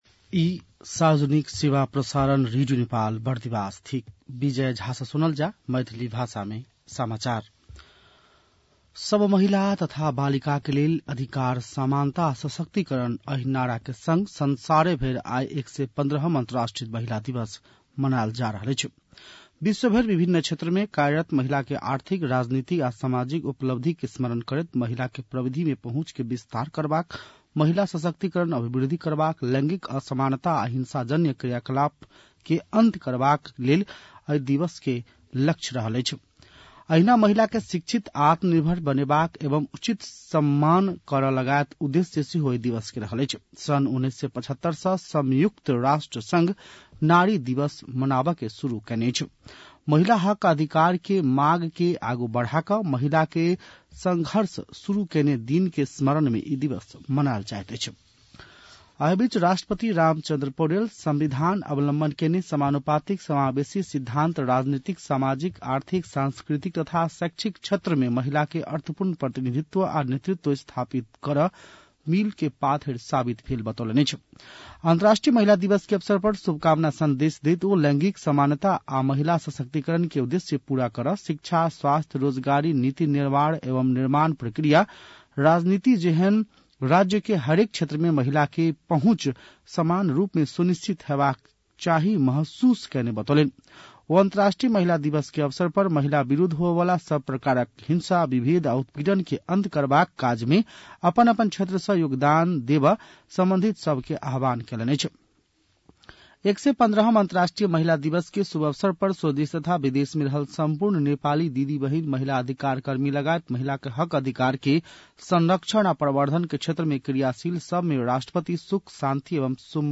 मैथिली भाषामा समाचार : २५ फागुन , २०८१